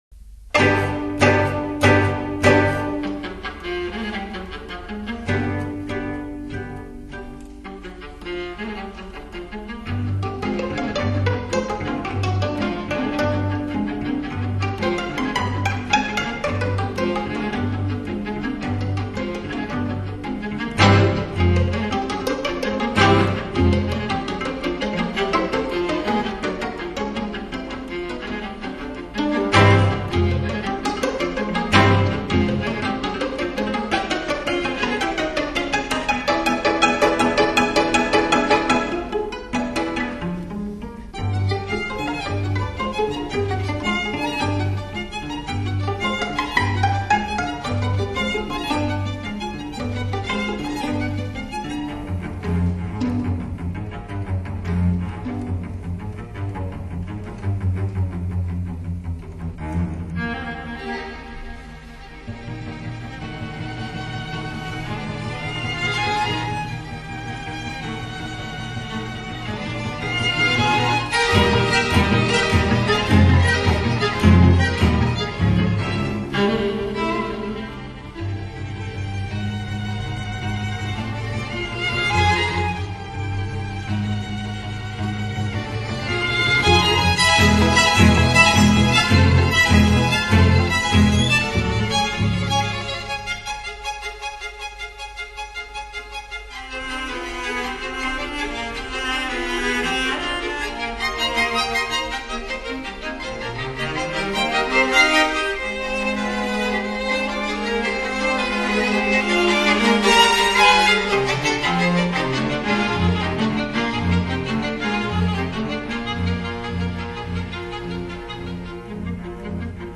录    音:Vevey,Switzerland,8/1965